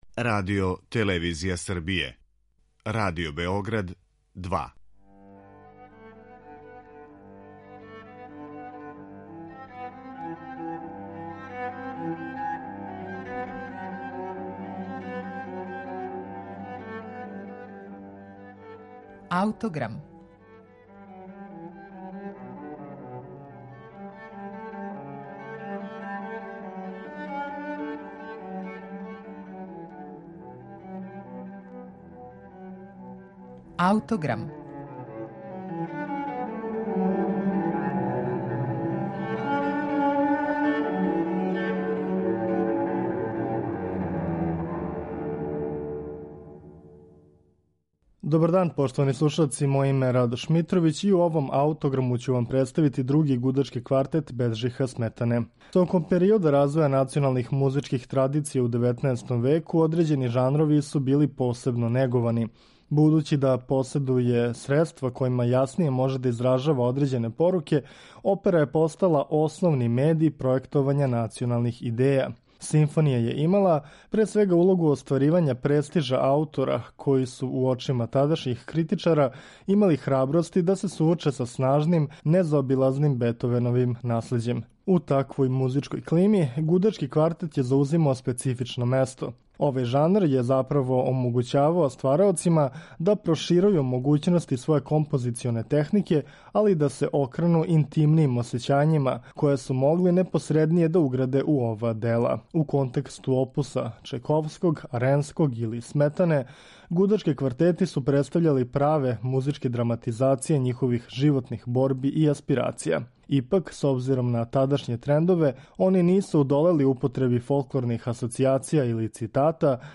Крећући се карактерно између гнева, патње и меланхолије, овај квартет представља својеврсну интимну исповест чешког композитора, који ће убрзо након завршетка дела и преминути. Други гудачки квартет Беджиха Сметане слушаћемо у извођењу Квартета „Штамиц".